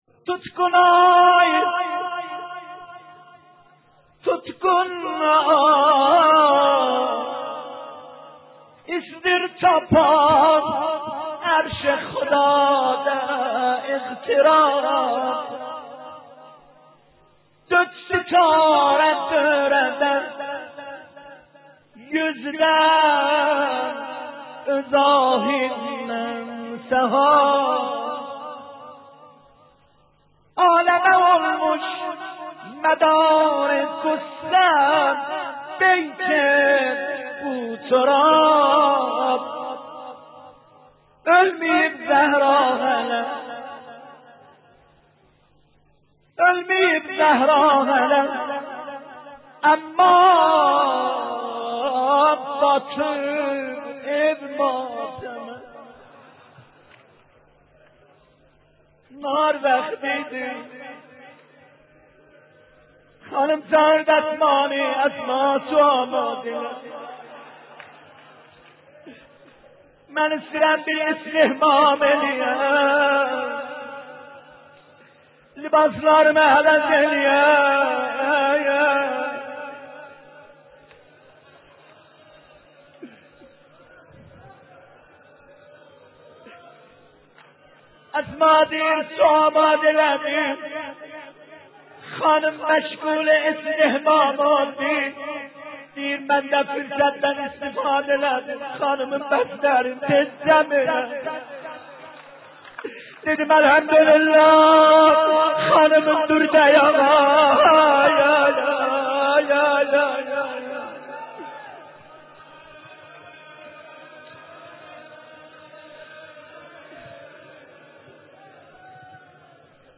دانلود مداحی المیوب زهرا اما باتیب او ماتم - دانلود ریمیکس و آهنگ جدید
روضه